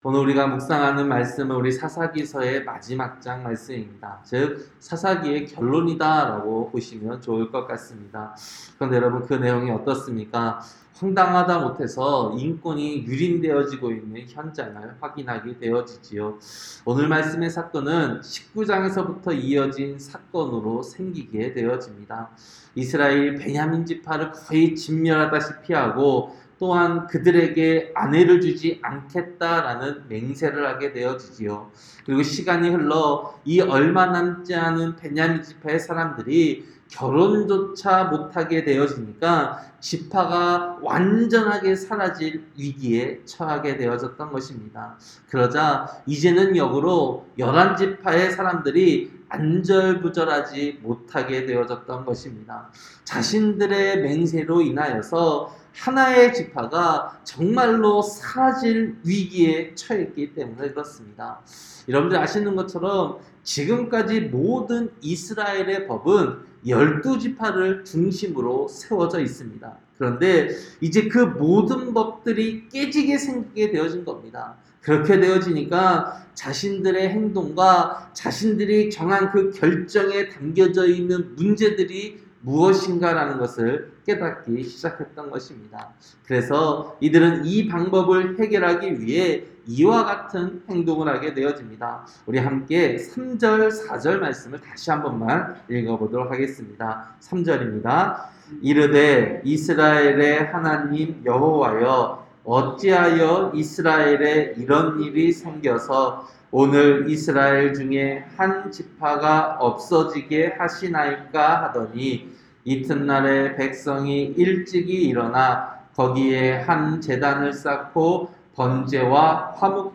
새벽설교-사사기 21장